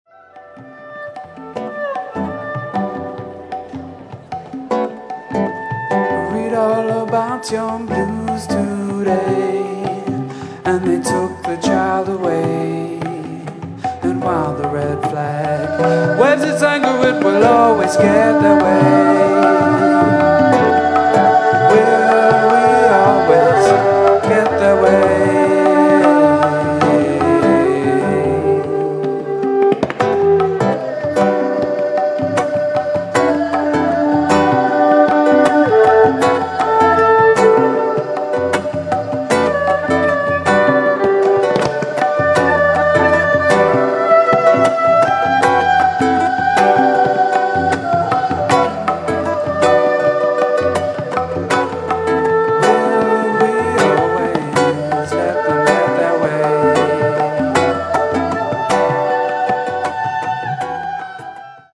Original song with special guest Tibetan singer
(2 x vocals, guitar, violin, tabla)